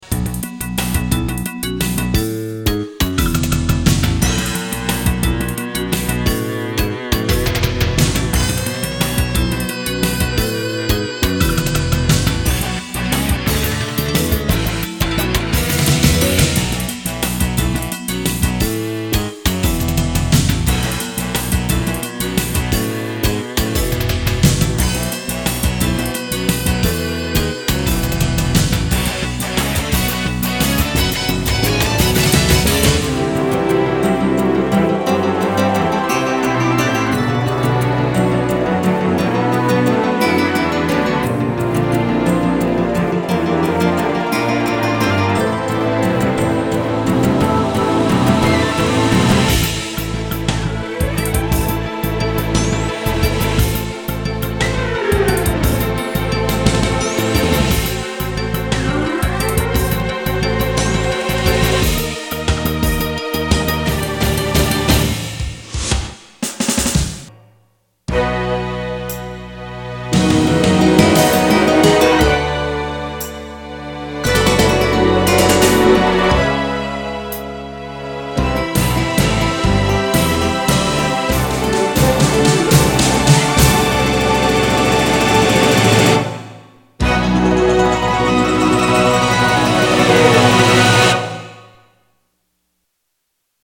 Spacy Rhythmic